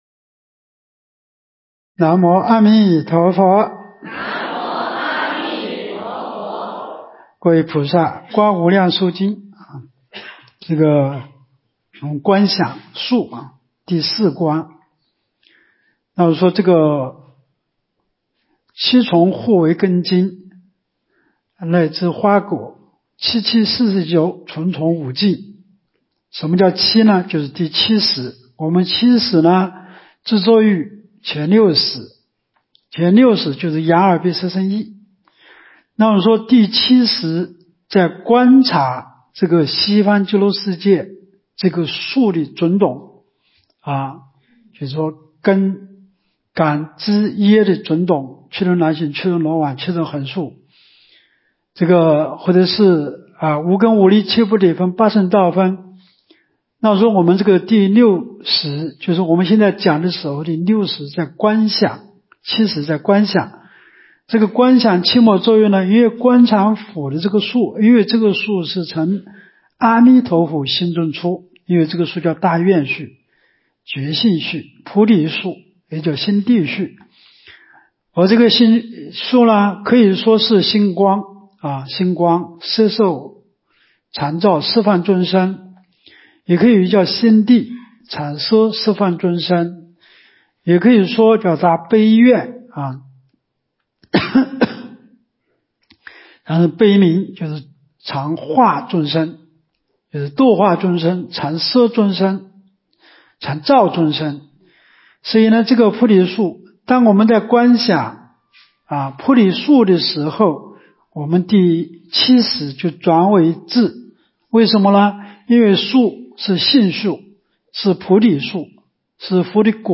无量寿寺冬季极乐法会精进佛七开示（22）（观无量寿佛经）...